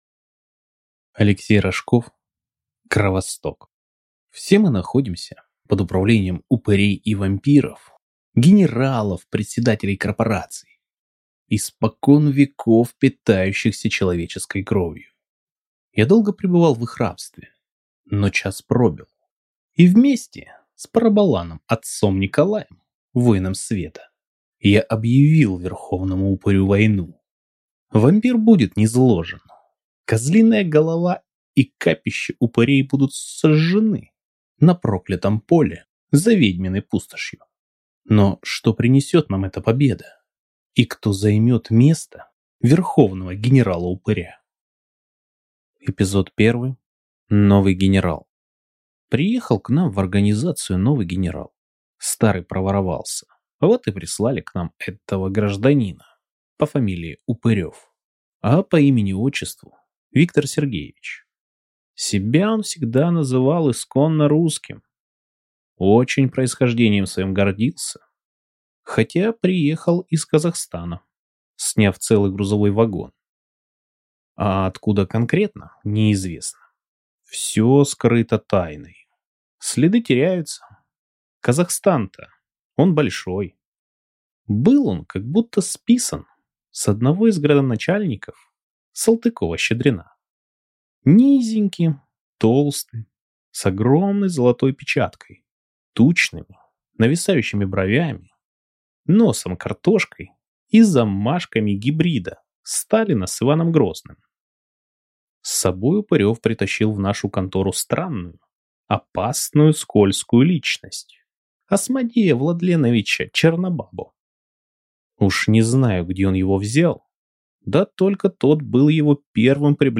Аудиокнига Кровосток | Библиотека аудиокниг